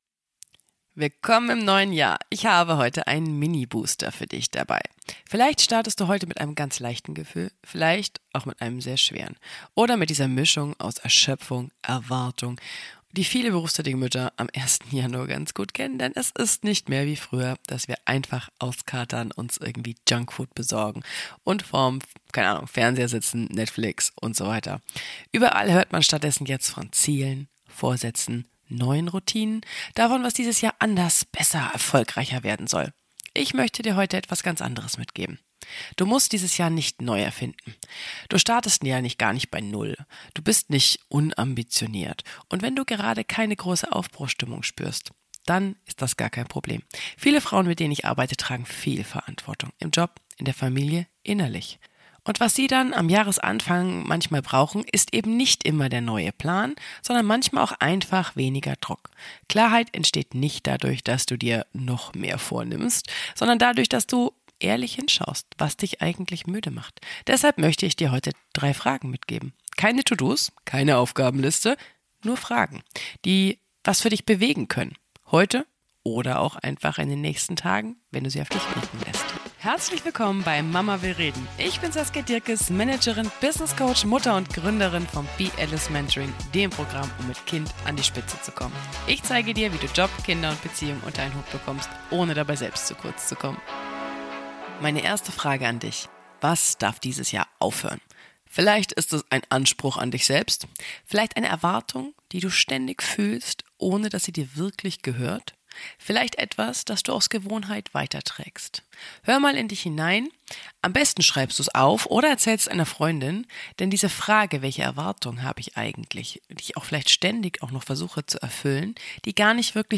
Ruhig.